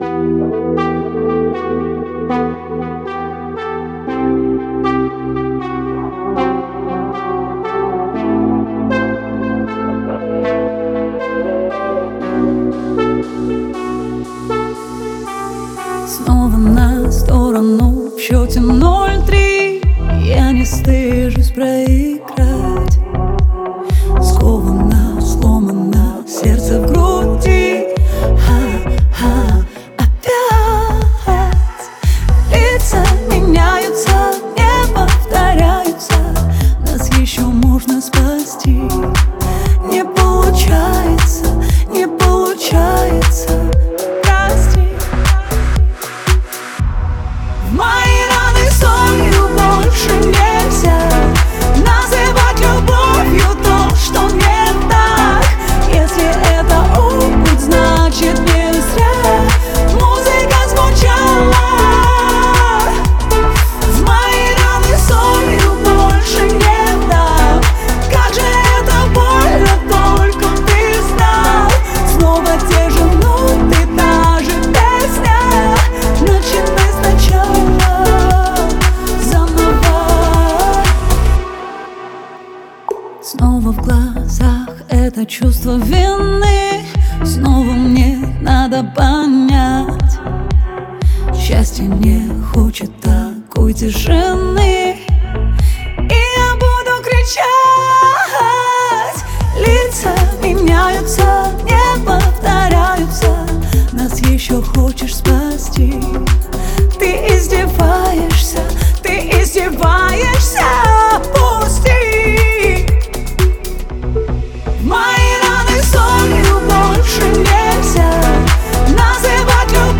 в жанре поп
Звучание трека характеризуется мелодичным вокалом